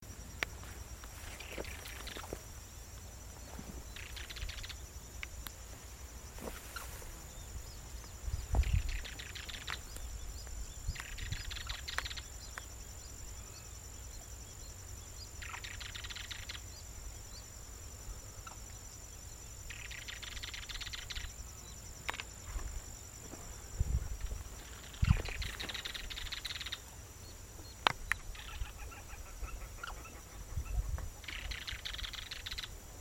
Chotoy (Schoeniophylax phryganophilus)
Nombre en inglés: Chotoy Spinetail
Fase de la vida: Adulto
Localidad o área protegida: Ceibas
Condición: Silvestre
Certeza: Observada, Vocalización Grabada